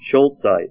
Help on Name Pronunciation: Name Pronunciation: Scholzite + Pronunciation
Say SCHOLZITE Help on Synonym: Synonym: ICSD 4270   PDF 27-95